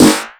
Index of /90_sSampleCDs/USB Soundscan vol.20 - Fresh Disco House I [AKAI] 1CD/Partition C/09-SNARES